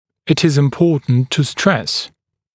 [ɪt ɪz ɪm’pɔːtnt tə stres][ит из им’по:тнт ту стрэс]важно подчеркнуть (, что…)